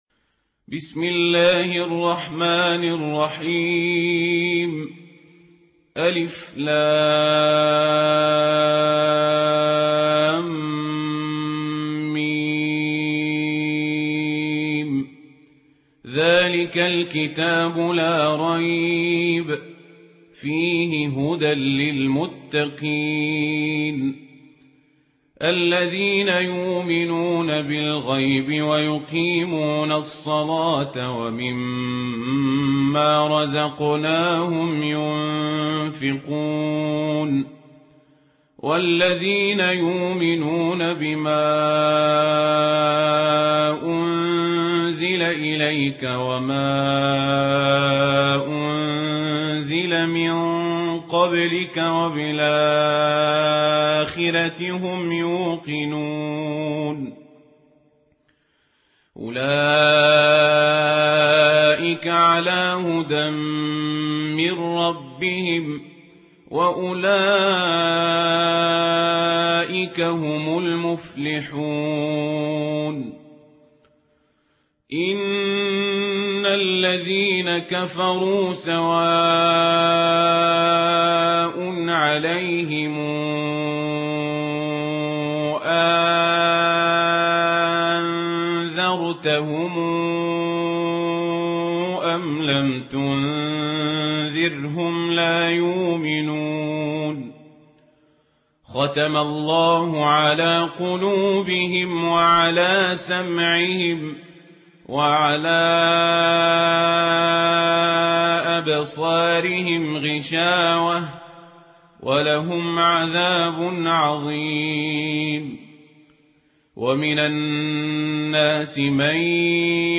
موقع نور القرأن | القران الكريم بصوت القارئ عمر القزابري